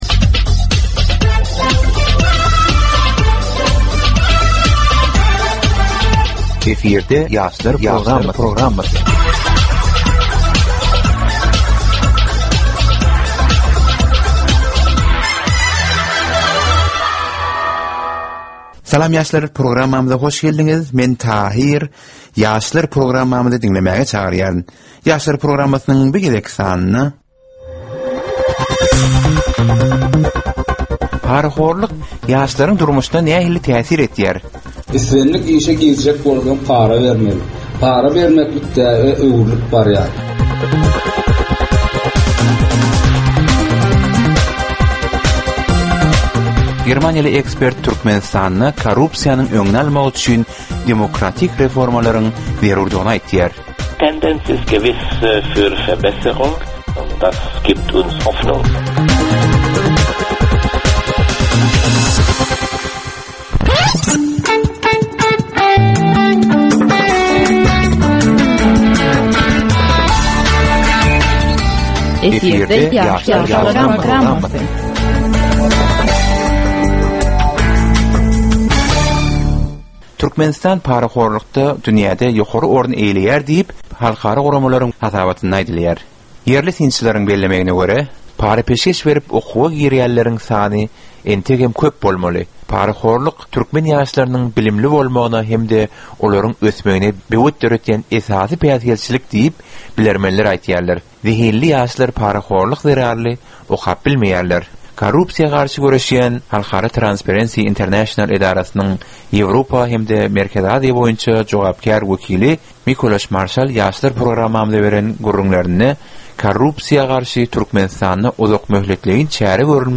Türkmen we halkara yaslarynyn durmusyna degisli derwaýys meselelere we täzeliklere bagyslanylyp taýýarlanylýan 15 minutlyk ýörite geplesik. Bu geplesiklde ýaslaryn durmusyna degisli dürli täzelikler we derwaýys meseleler barada maglumatlar, synlar, bu meseleler boýunça adaty ýaslaryn, synçylaryn we bilermenlerin pikrileri, teklipleri we diskussiýalary berilýär. Geplesigin dowmynda aýdym-sazlar hem esitdirilýär.